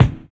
DISCO 12 BD.wav